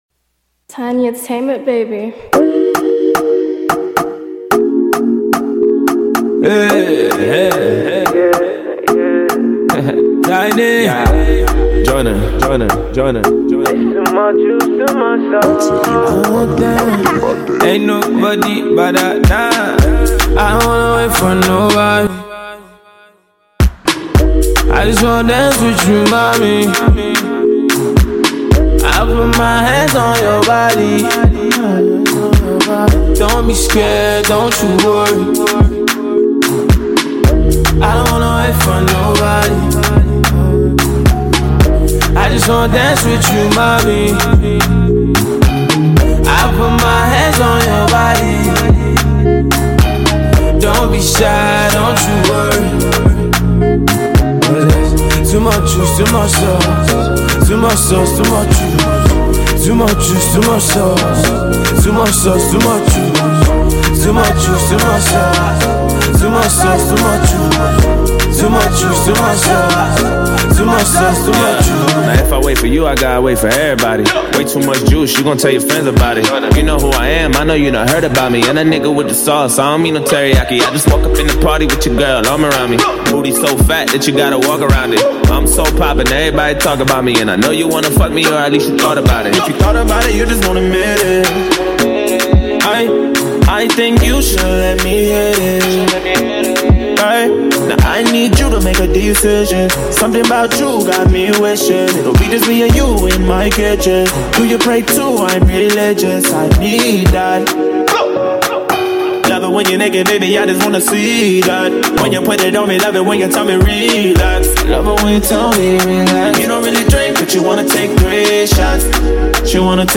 American rapper
It’s the perfect hip hop vibe.